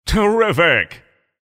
voice_tier3_terrific.mp3